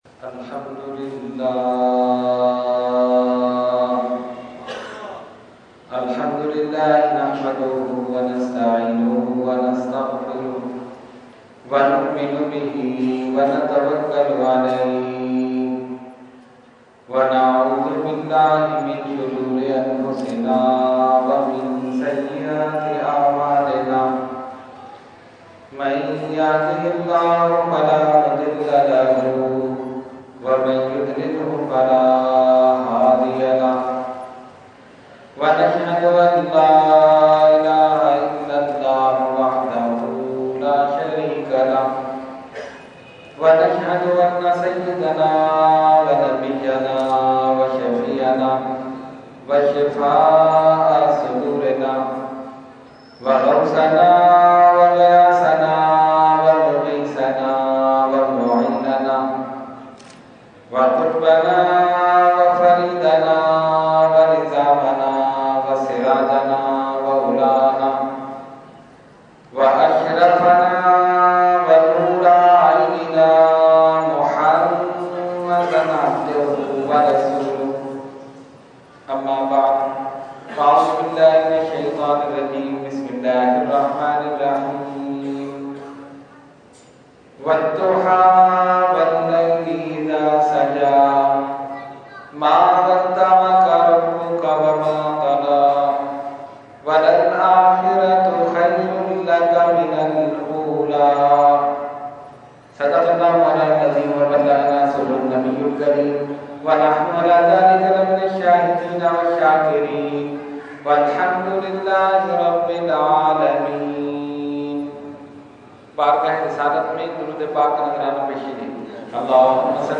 Category : Speech | Language : UrduEvent : Mehfil Milad Ghousia Masjid Liaqatabad 25 January 2014